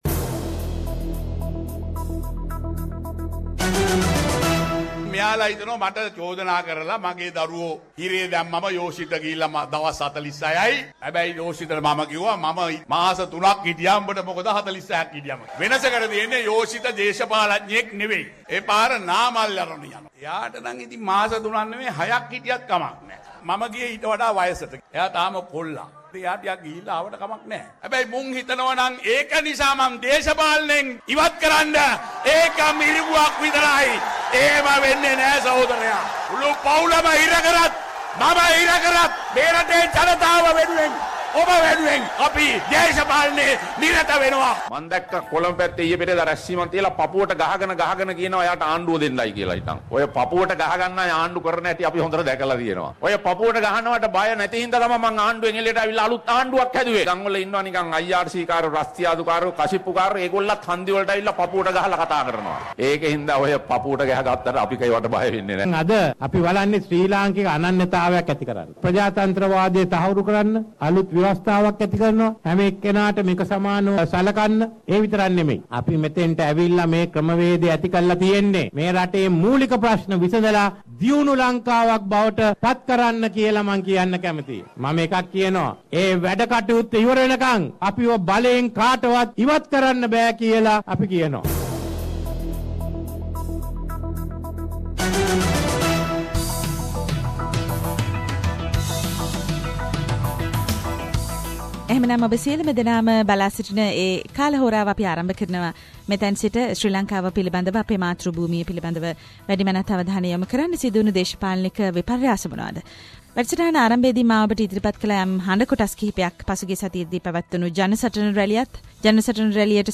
Journalist - (current affair) reports from Sri Lanka